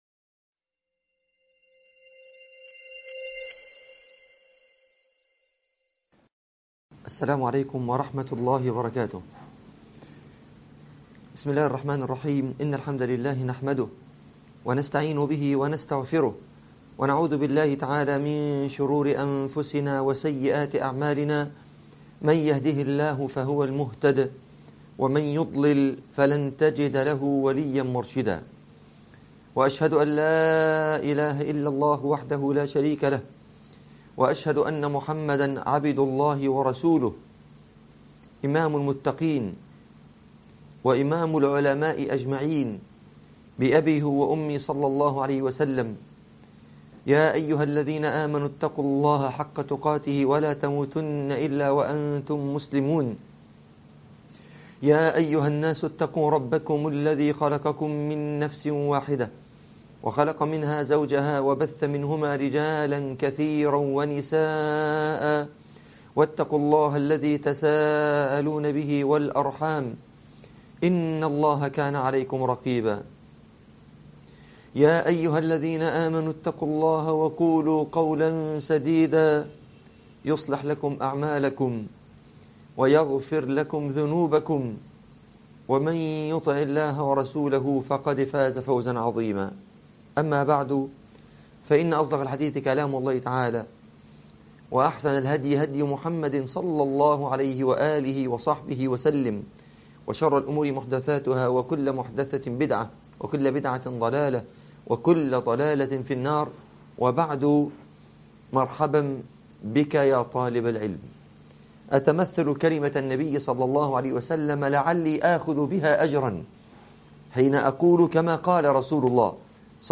عنوان المادة العمل عبادة || محاضرة 2 || شرح كتاب حلية طالب العلم تاريخ التحميل الثلاثاء 23 يناير 2024 مـ حجم المادة 25.53 ميجا بايت عدد الزيارات 203 زيارة عدد مرات الحفظ 135 مرة إستماع المادة حفظ المادة اضف تعليقك أرسل لصديق